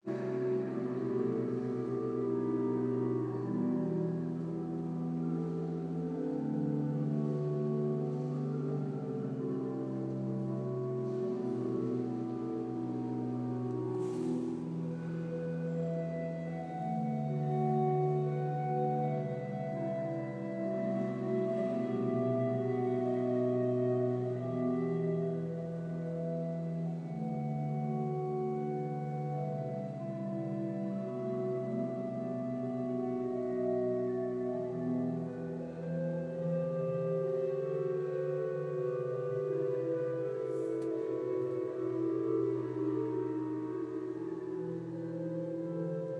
orgel halb 12